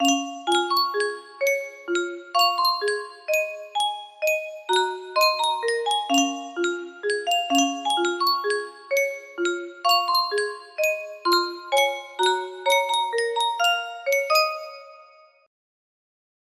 Yunsheng Music Box - Unknown Tune 1004 music box melody
Full range 60